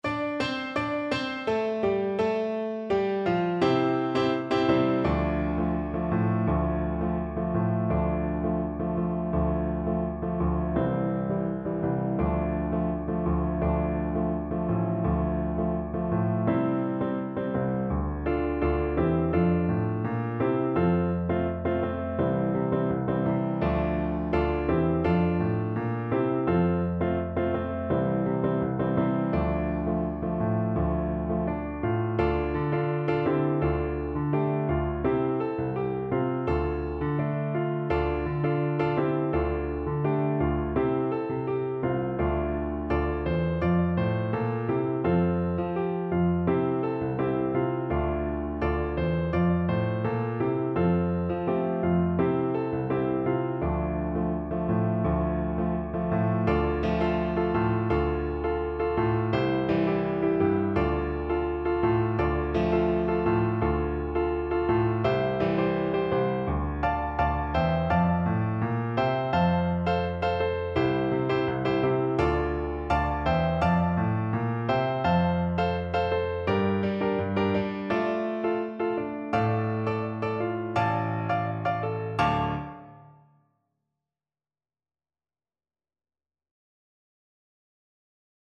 In two =c.84
4/4 (View more 4/4 Music)
Traditional (View more Traditional French Horn Music)
world (View more world French Horn Music)